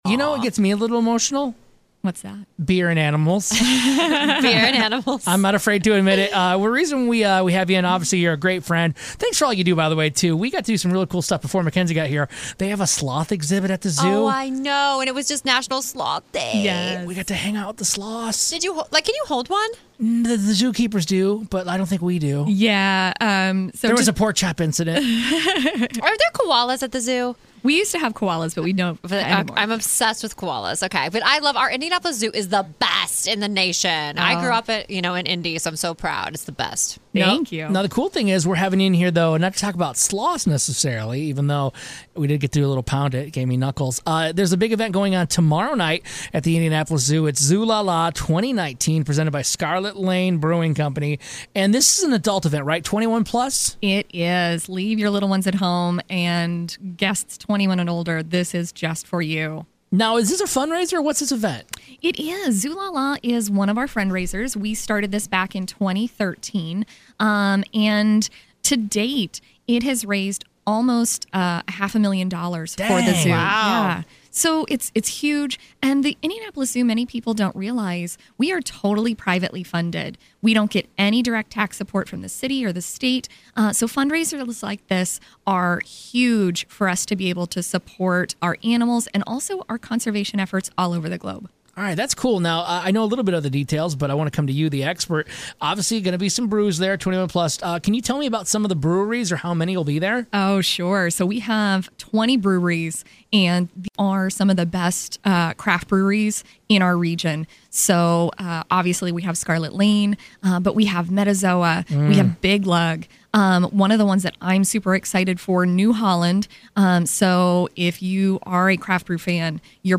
We interview someone from the zoo to talk about the great things they've got going on this fall!